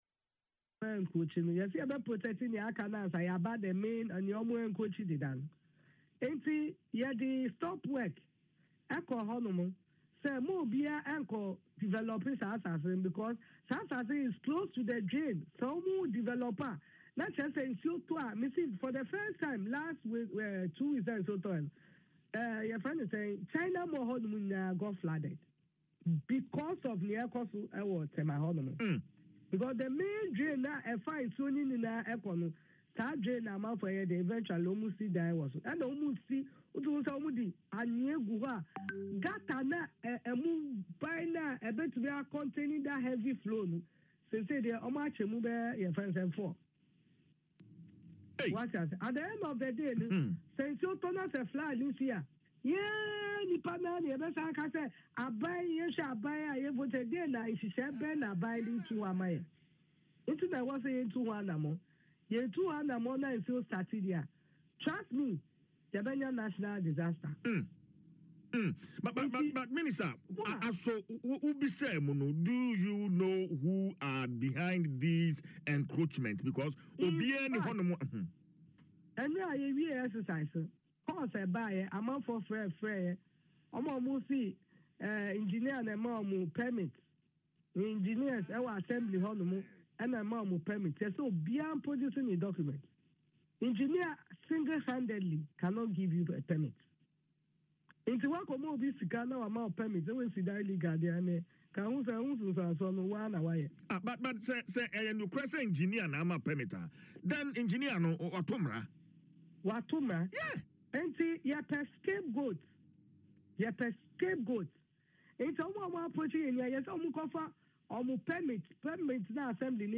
Speaking on Adom FM’s Dwaso Nsem following a demolition exercise at the Tema Ramsar site on Wednesday, Madam Ocloo, who also serves as the Member of Parliament for Shai-Osudoku, expressed concerns over these alleged irregularities.
Linda-Ocloo-on-Ramsar-sites.mp3